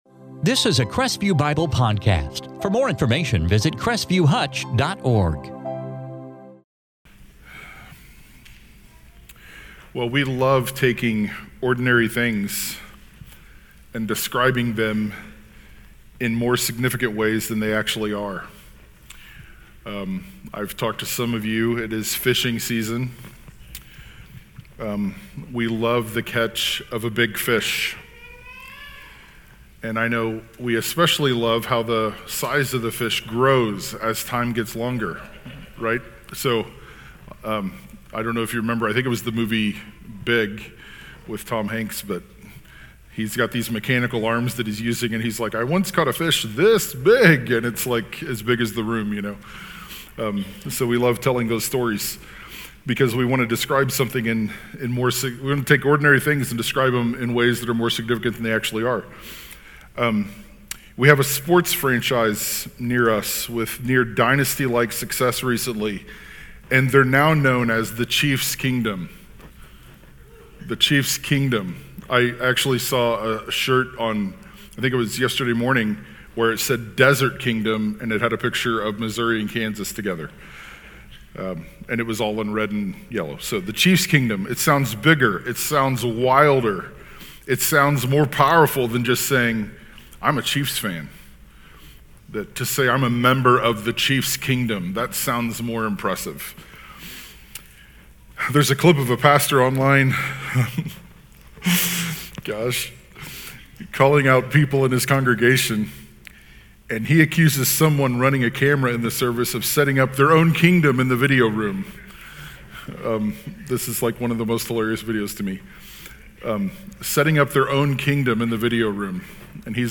2024 Gospel of Luke Luke 13:1-21 Some specific ways of living characterize Jesus's kingdom. In this sermon from Luke 13:1-21